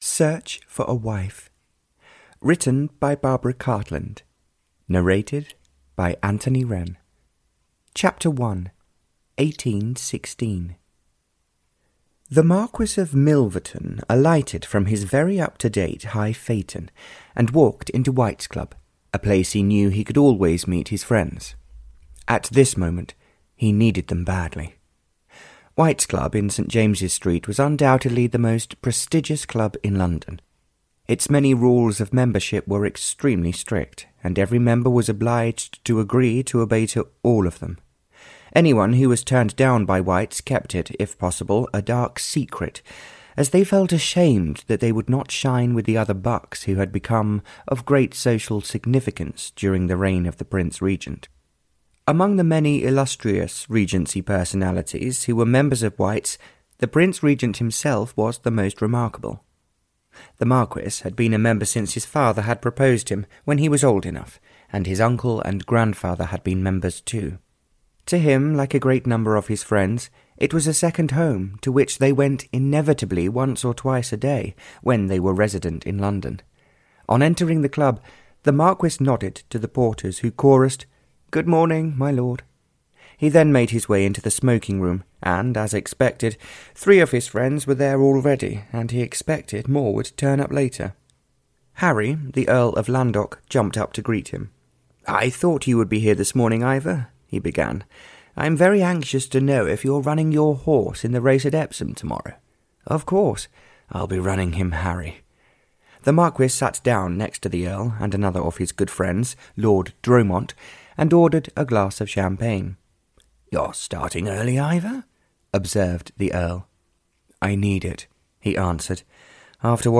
Audio knihaSearch for a Wife (Barbara Cartland s Pink Collection 86) (EN)
Ukázka z knihy